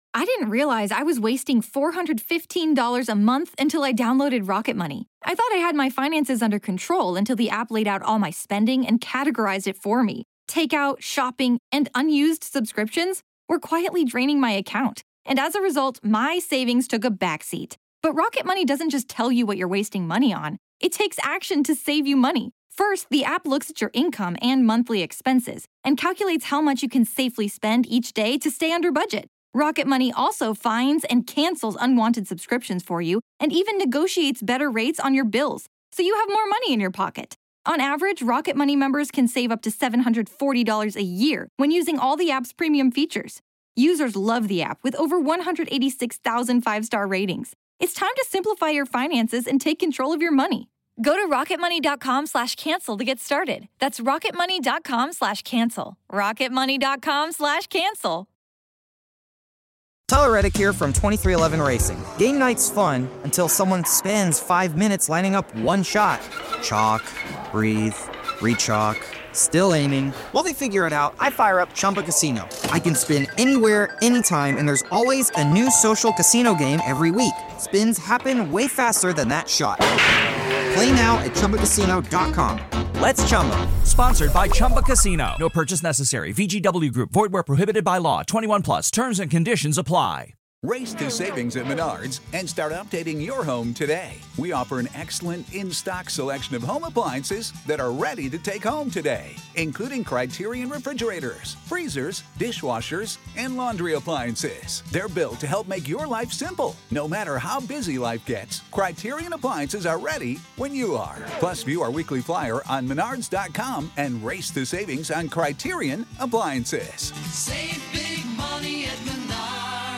Your Qs, our As (LIVE EP)
Comedians and dearest pals Tom Allen and Suzi Ruffell chat friendship, love, life, and culture... sometimes...